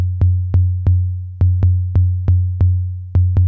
SINE GROOV-R.wav